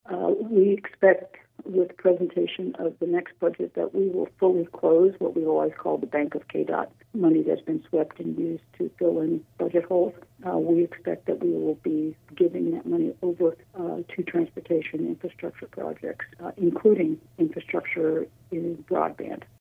Governor Laura Kelly spoke with News Radio KMAN this week during an exclusive interview heard Wednesday on KMAN’s In Focus.